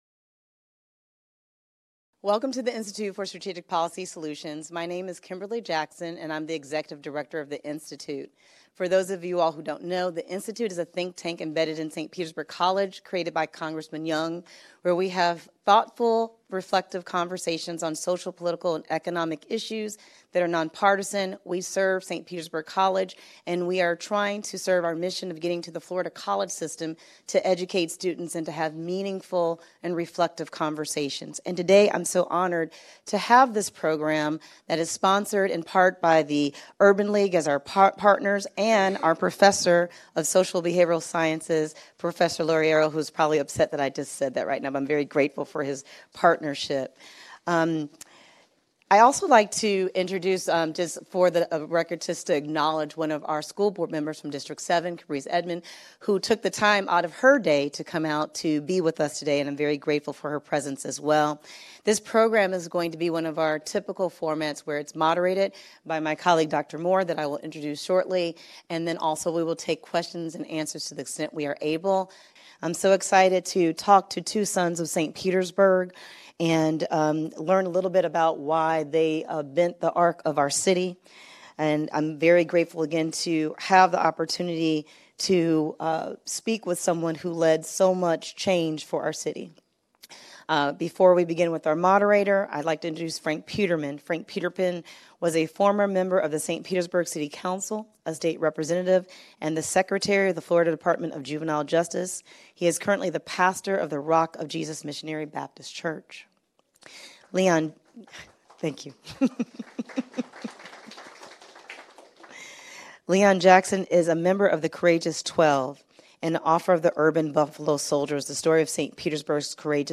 SPC Gibbs Campus A discussion with students and community members about Black history in St. Petersburg